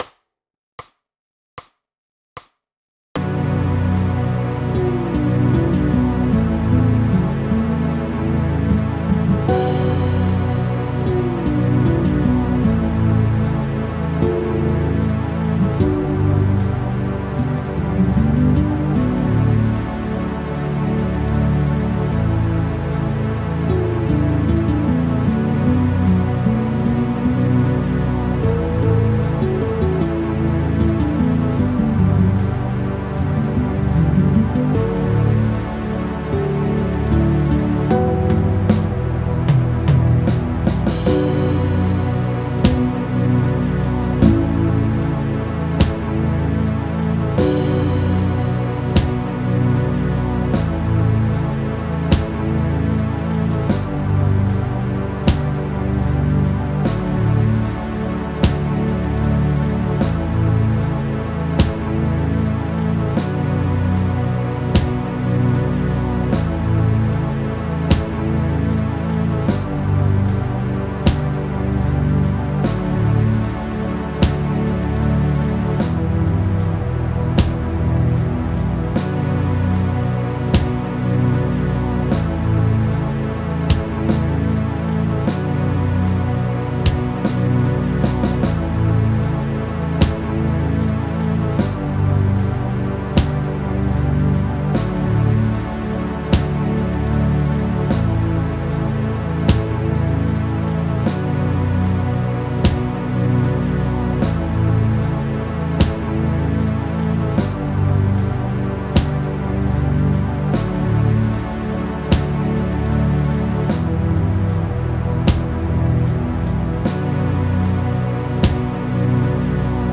Minor_Scales_B_Minor_Pentascale.mp3